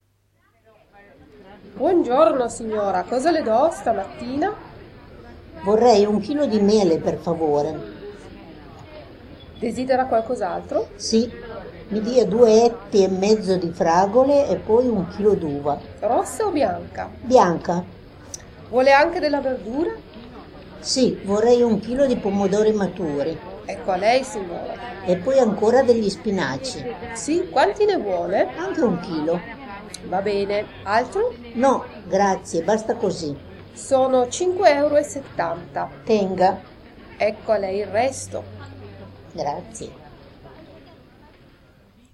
In jeder Übung sind Muttersprachler zu hören, die sich mit in einer Fremdsprache sprechenden Personen unterhalten.